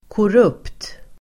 Ladda ner uttalet
korrupt.mp3